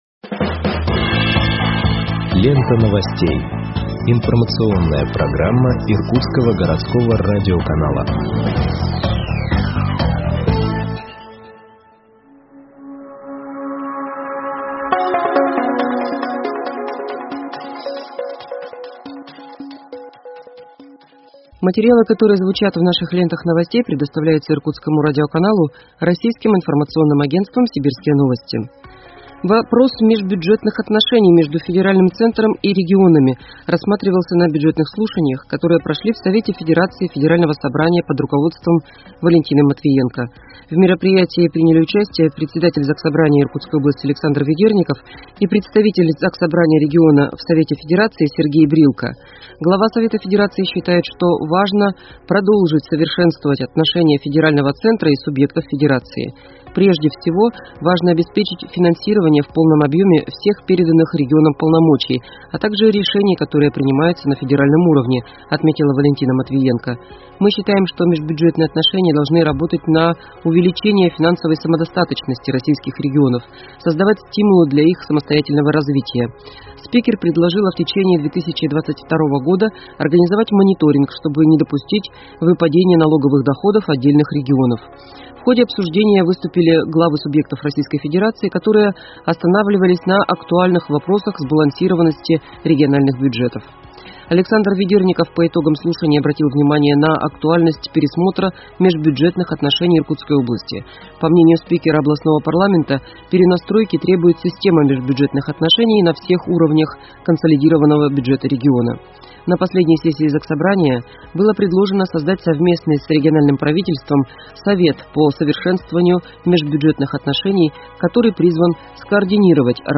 Выпуск новостей в подкастах газеты Иркутск от 07.10.2021 № 2